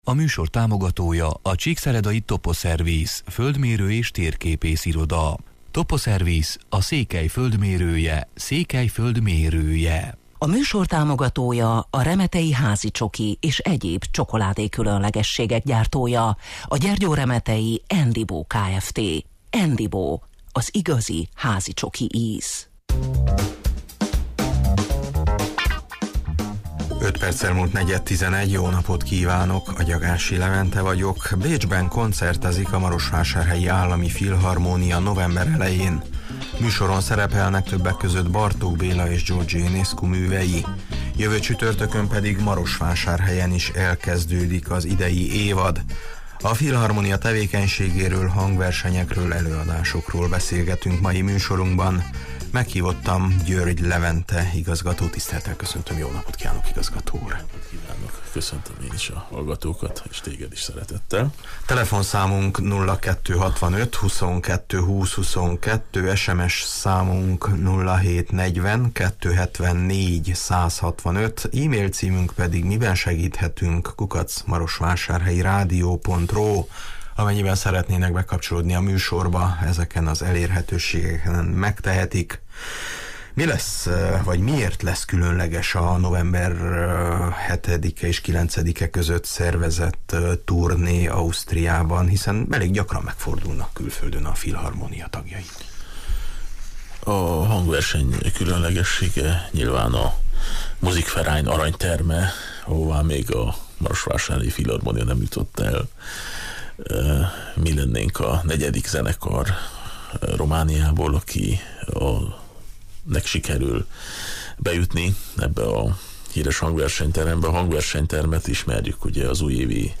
A filharmónia tevékenységéről, hangversenyekről, előadásokról beszélgetünk mai műsorunkban.